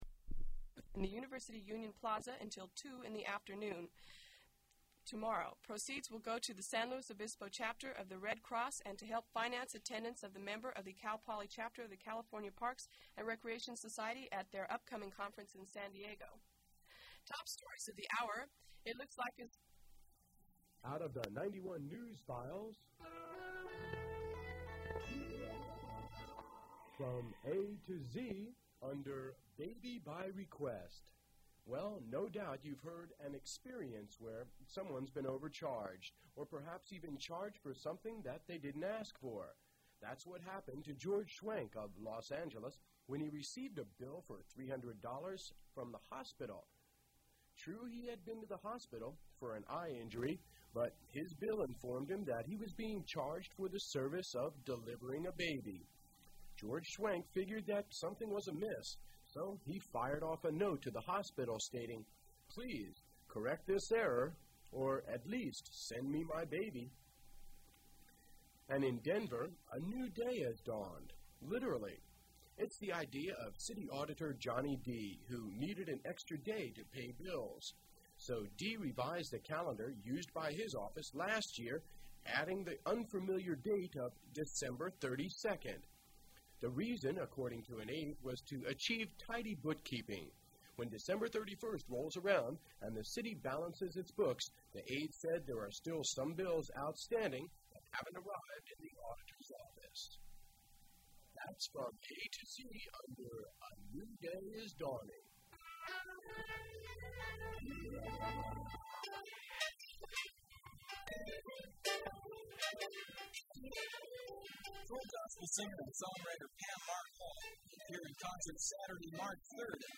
Interview with football player
Microwave Oven PSA
Water Conservation PSA